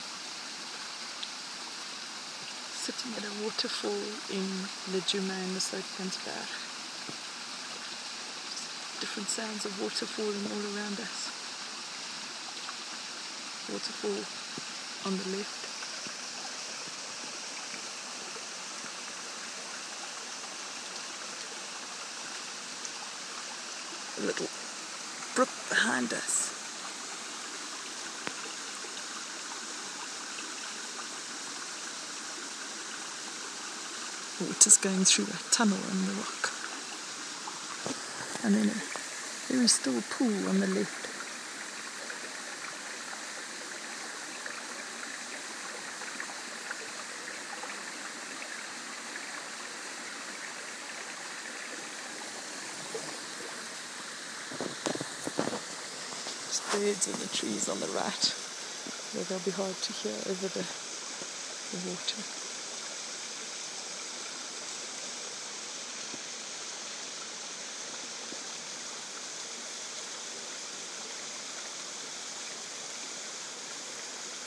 Sounds at a waterfall; Lajuma
Sitting still below a small waterfall at the research station at Lajuma, Soutpansberg. Fascinated my the variety of sounds water makes as it flows over rock.